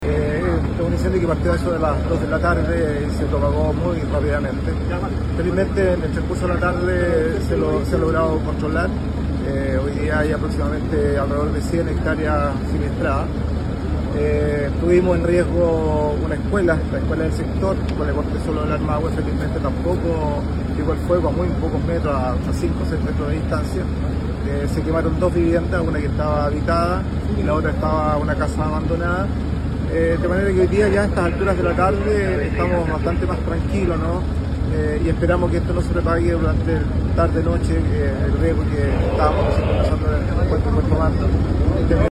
El alcalde de Pichidegua Adolfo Cerón informó que para las 15 horas de la tarde de este jueves el incendio ya estaba controlado, escuchemos: